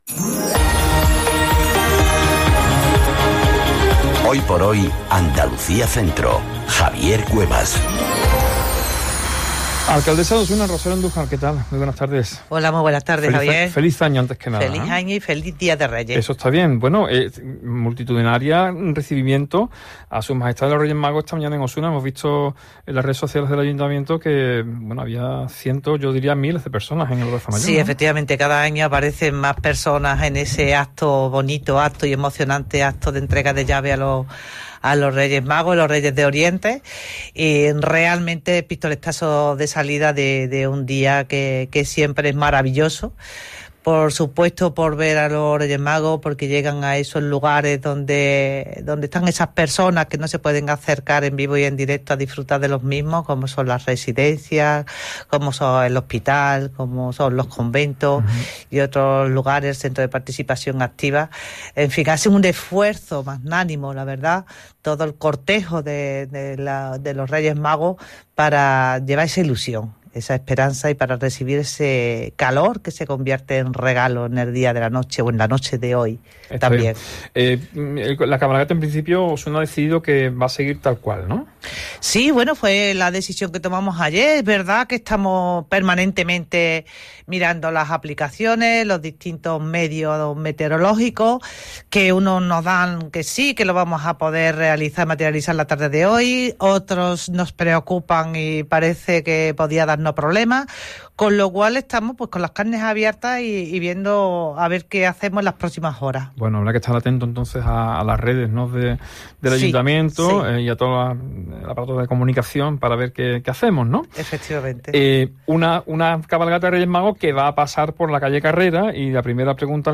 ENTREVISTA | Rosario Andújar hace balance de 2025 - Andalucía Centro